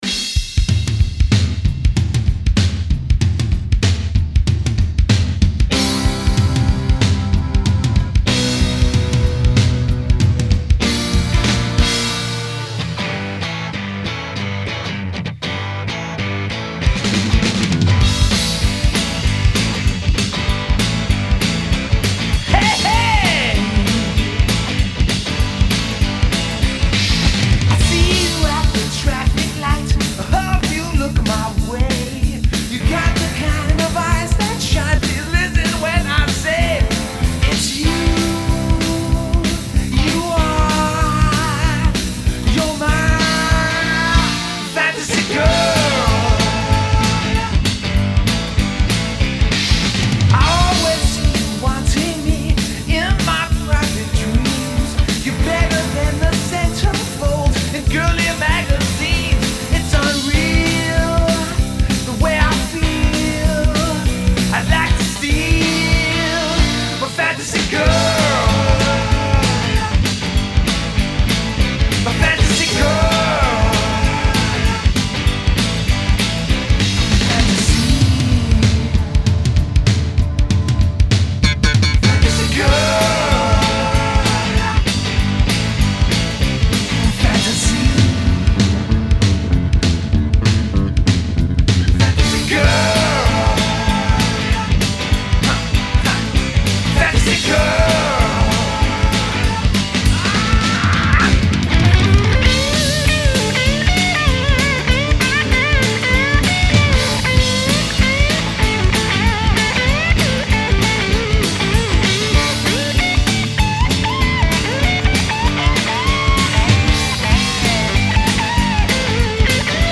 Guitar & Vocals
Drums & Percussion